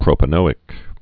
(prōpə-nōĭk)